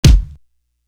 Bumpy Kick.wav